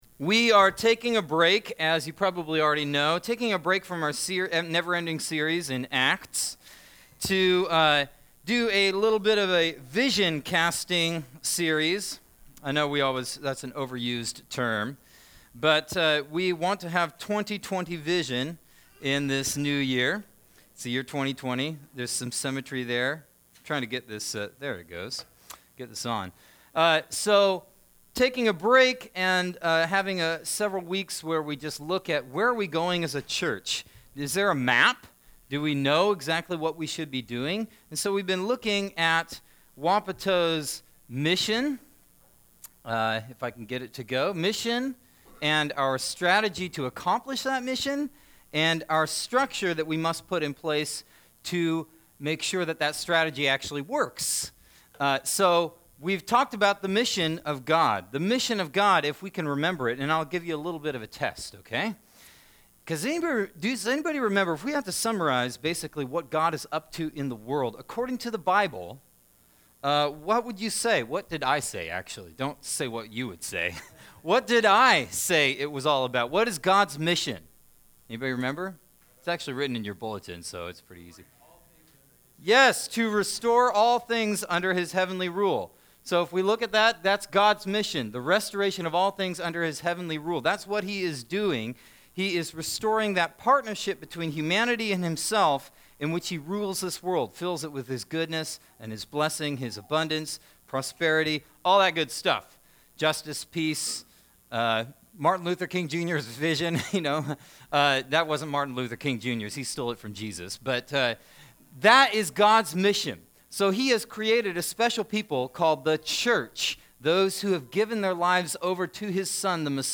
Sermons - Wapato Valley Church